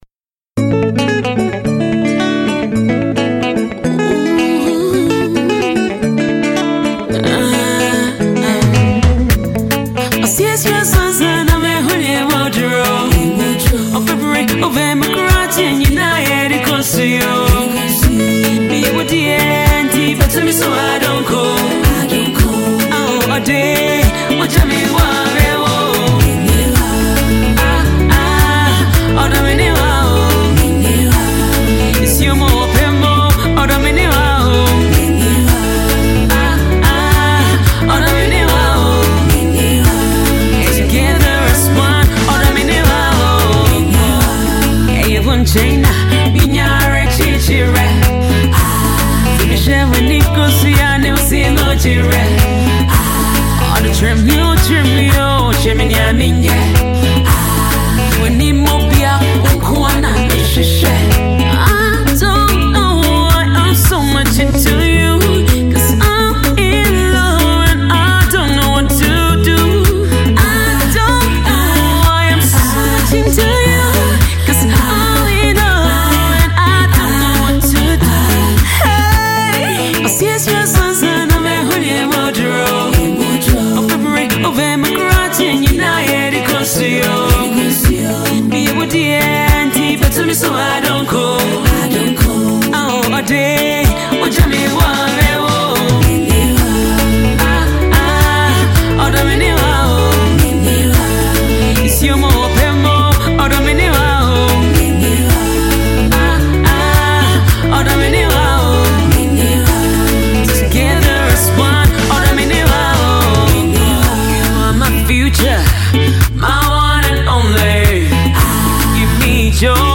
highlife jam
Ghanaian female songstress